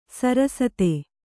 ♪ sarasate